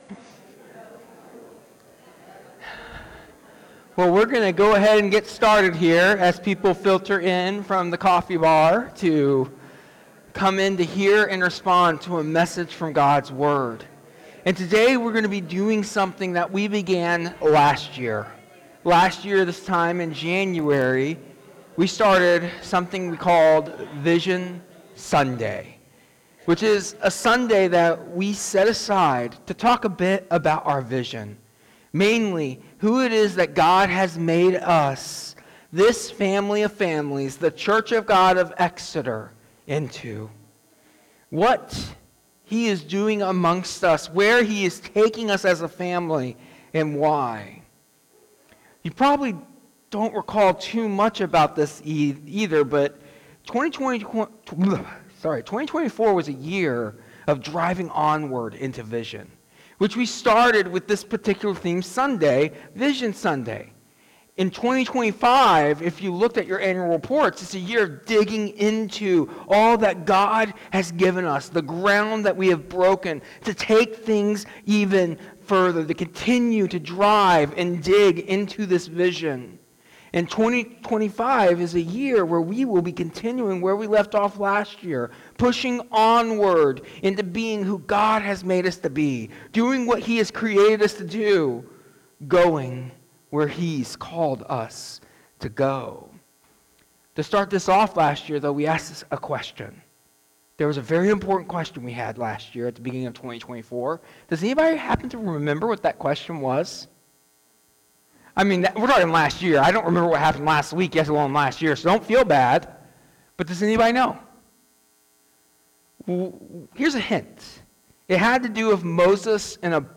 In this sermon, we will highlight the vision of the Church of God of Exeter as we enter 2025, encouraging each other to reflect on our identity and purpose as image bearers of God.